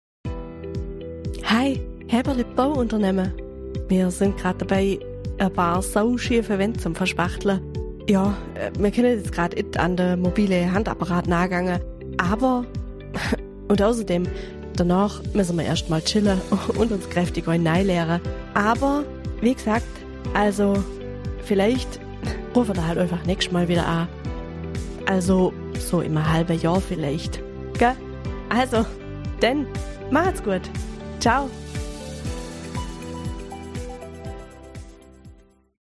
Professionelle Sprecherin
Lustige Ansage